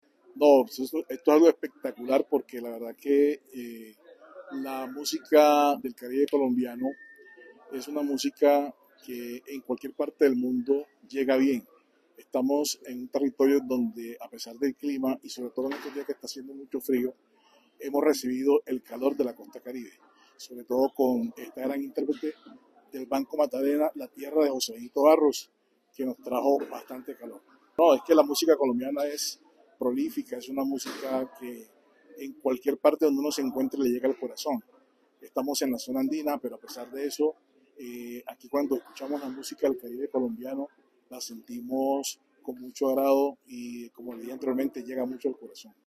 Asistente al concierto